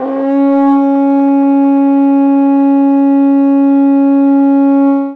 Index of /90_sSampleCDs/Best Service ProSamples vol.52 - World Instruments 2 [AKAI] 1CD/Partition C/TENOR HORN